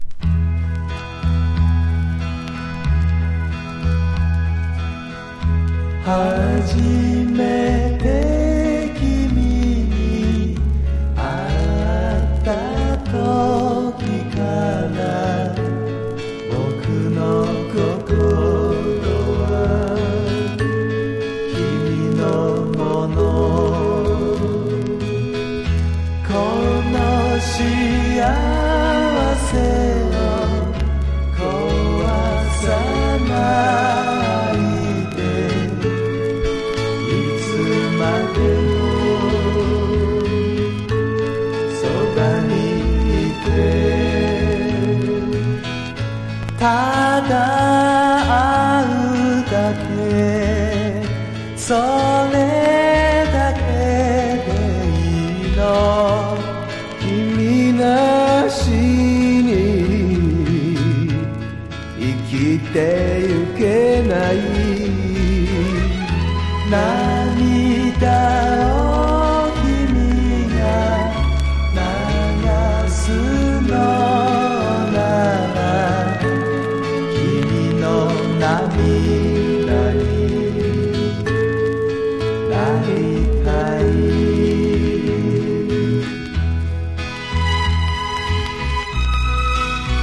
フォーク・グループ
A面はカレッジ・フォークな雰囲気ある